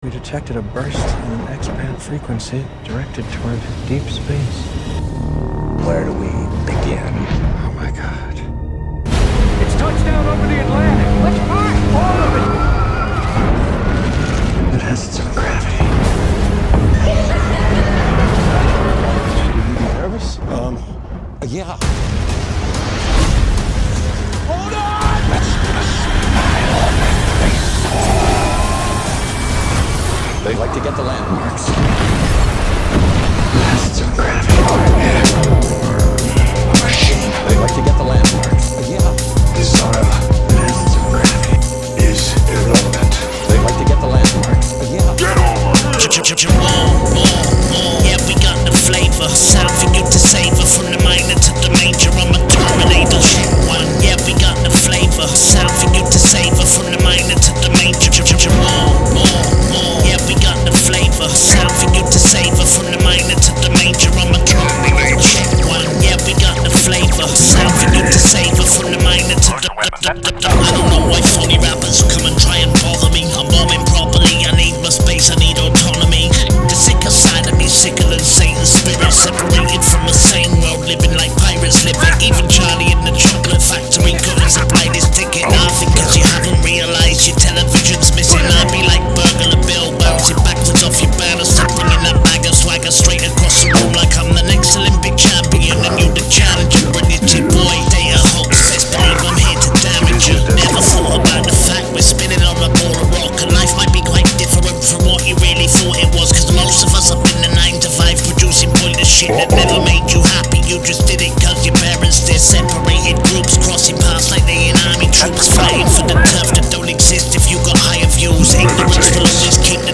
Hiphop
Vocals